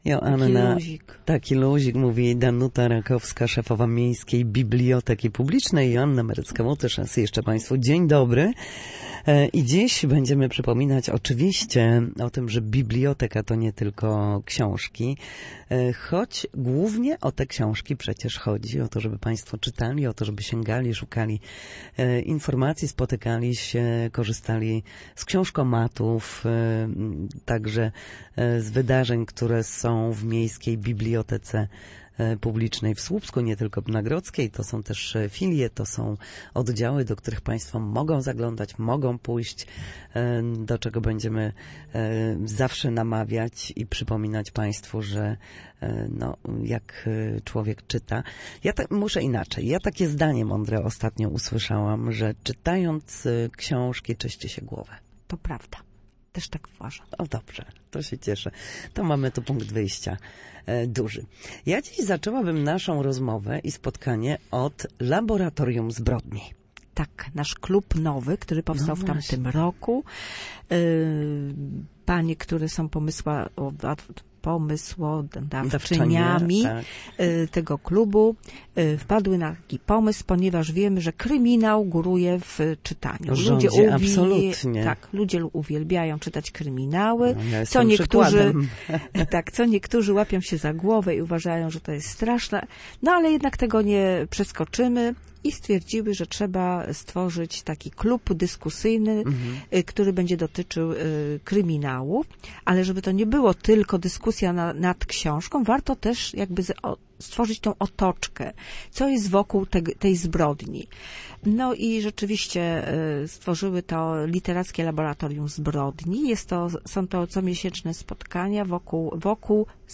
Kryminały, spotkania i czytelnicy. Rozmowa o działalności słupskiej biblioteki